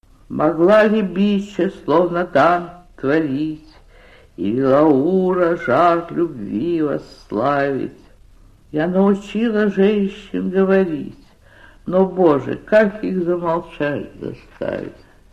anna-ahmatova-chitaet-avtor-epigramma-mogla-li-biche-slovno-dant-tvorit-1958